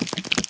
sounds / mob / spider / step4.ogg